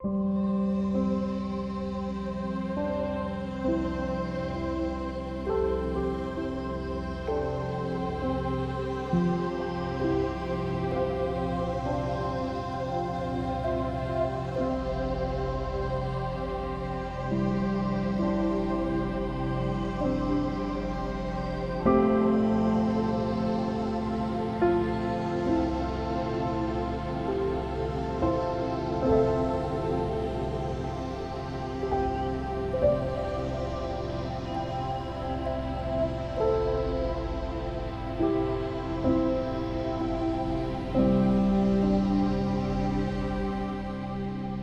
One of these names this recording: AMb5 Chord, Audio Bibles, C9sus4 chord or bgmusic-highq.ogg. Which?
bgmusic-highq.ogg